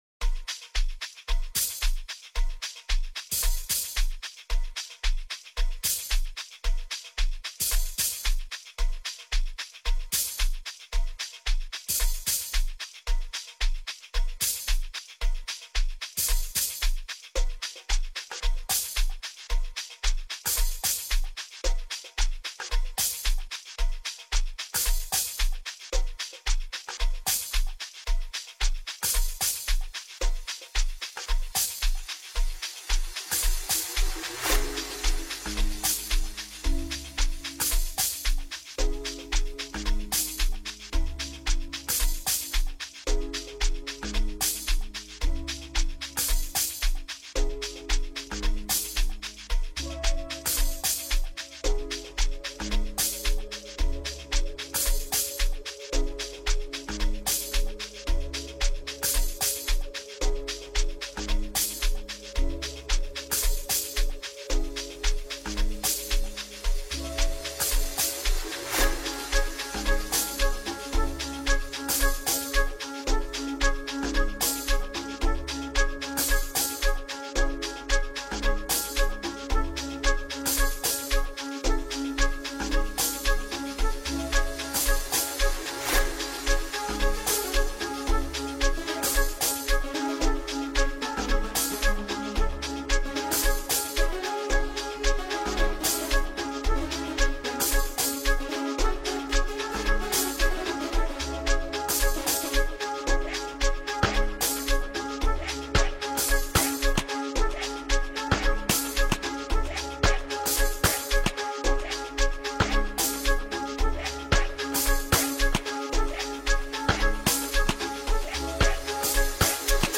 effortless feel-good and festival ready scorcher